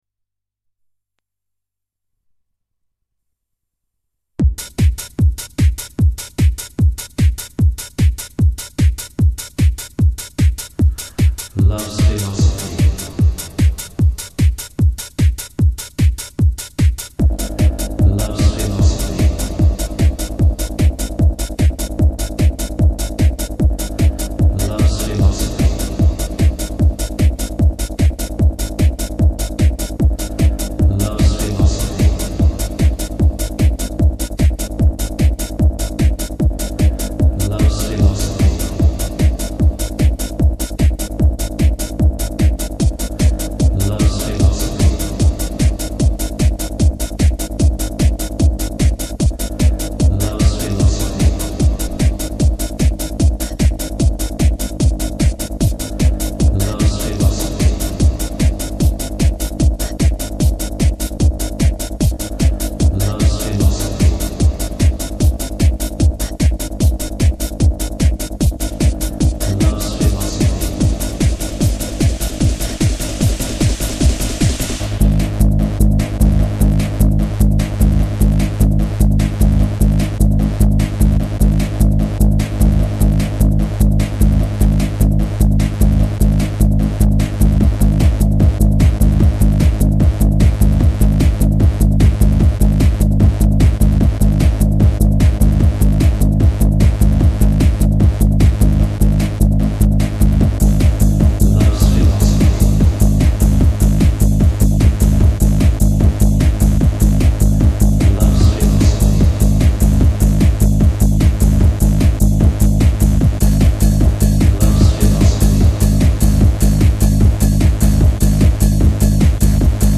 cut up into a headbanger version
with recitation samples